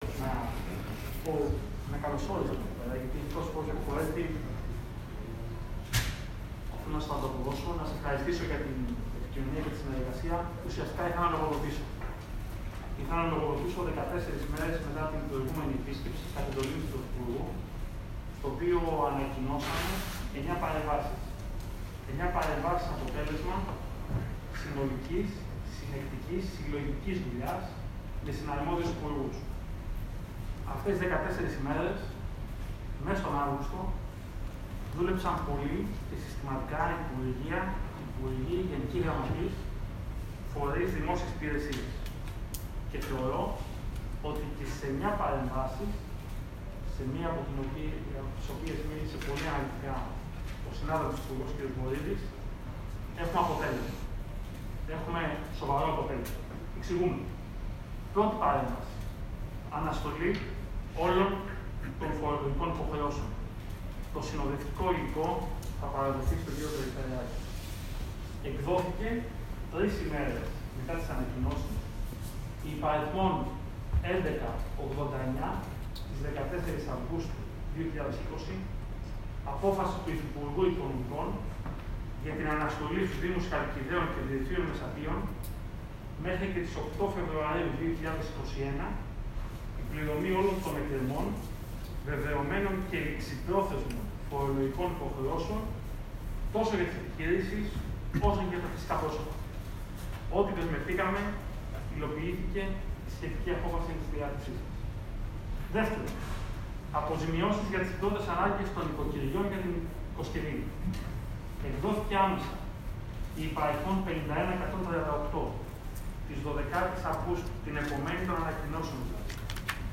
Αποκλειστικά οι δηλώσεις Βορίδη,Σταϊκούρα, και Σπανού μετά τη σύσκεψη στο διοικητήριο της Χαλκίδας [ηχητικό]
Ακούστε αποκλειστικά τι δήλωσαν ο Μάκης Βορίδης, ο Χρήστος Σταικούρας και ο Φάνης Σπανός.